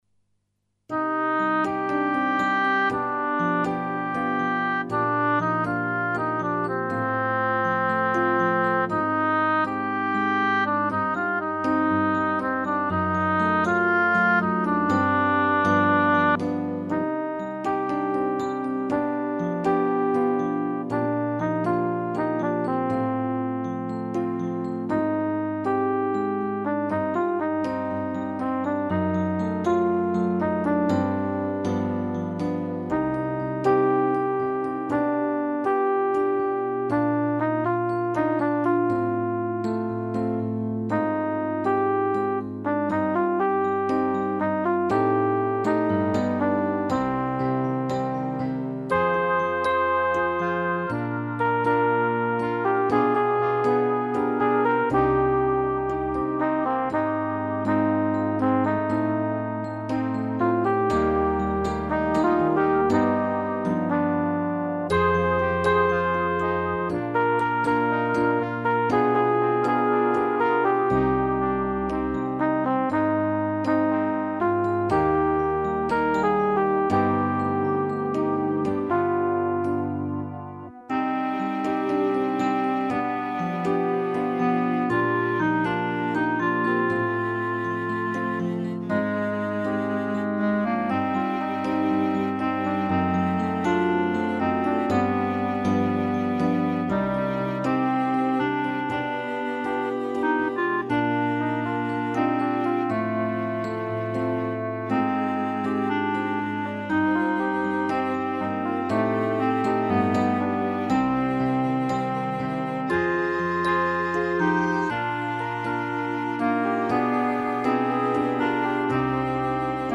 fichier de travail pour la voix 3
( deuxième voix d'accompagnement,